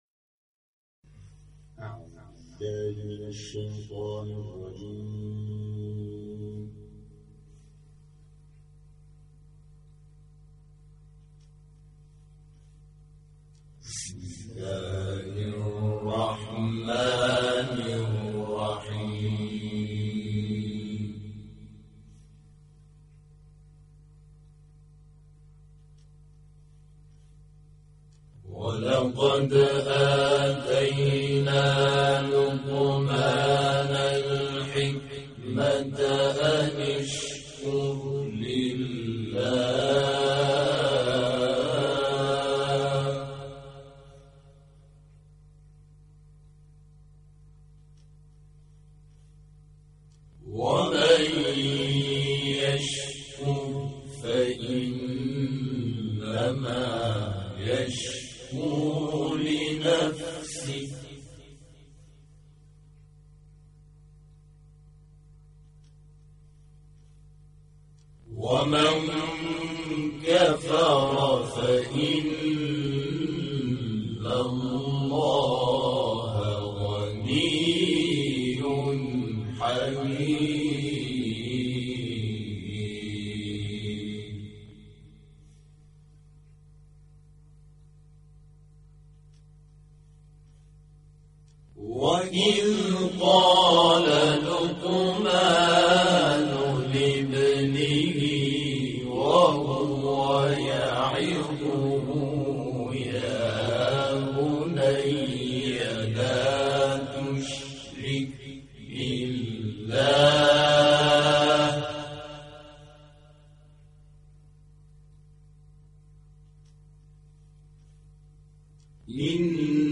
همخوانی سوره لقمان از گروه سیرت‌النبی
گروه شبکه اجتماعی: همخوانی آیاتی از سوره مبارکه لقمان اثری از گروه تواشیح و همخوانی سیرت‌النبی مشهد را می‌شنوید.